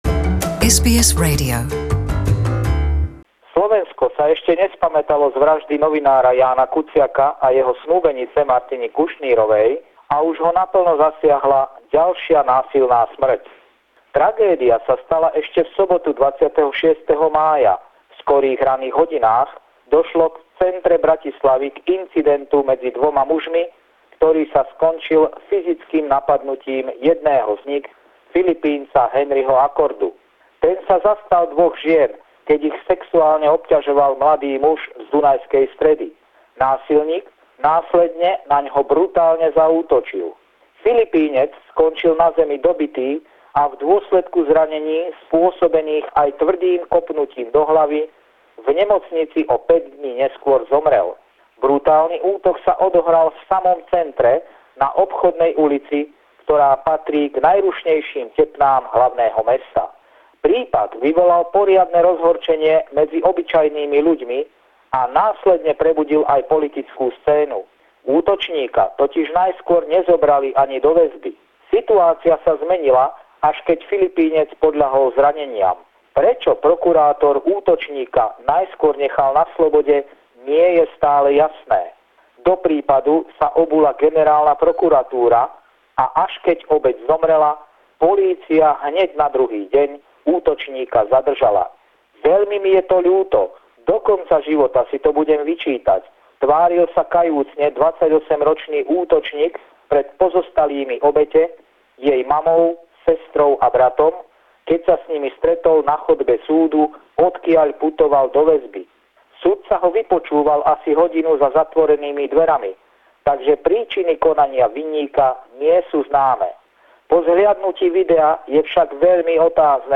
Pravidelný telefonát týždňa